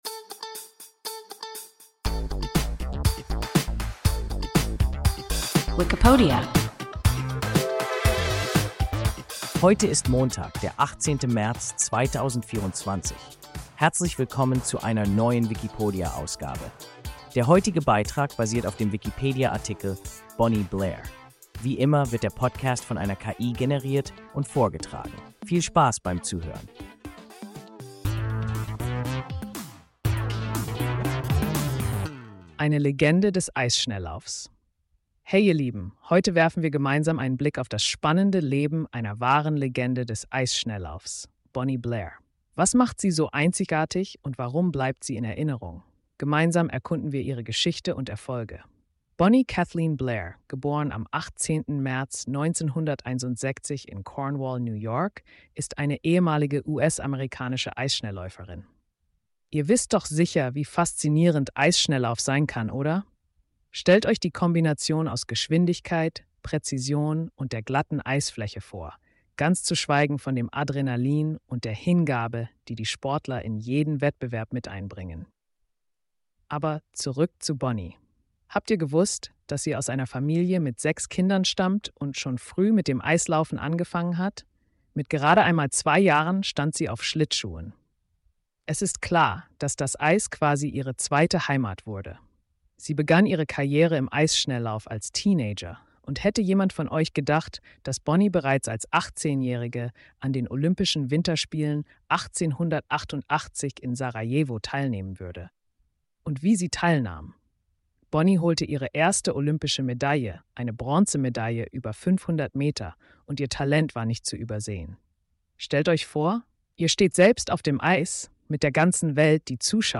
Bonnie Blair – WIKIPODIA – ein KI Podcast